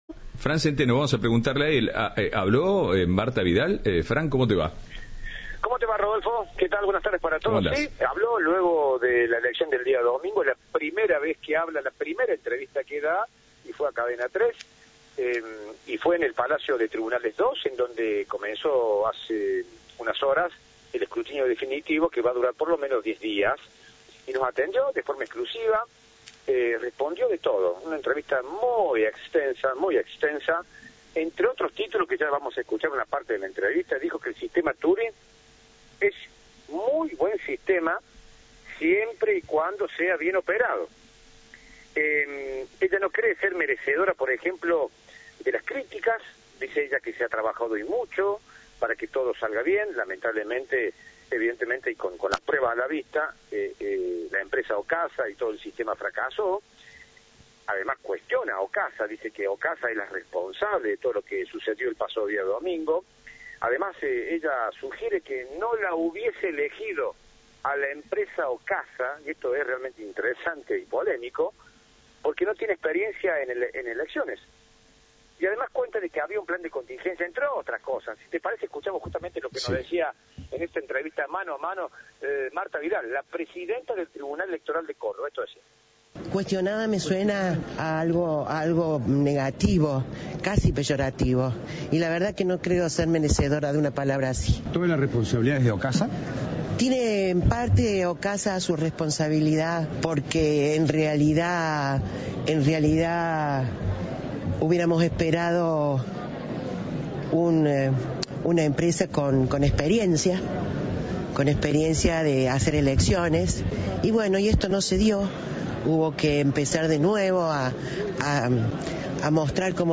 La presidenta de la Justicia Electoral de Córdoba habló con Cadena 3 tras las críticas recibidas por los comicios.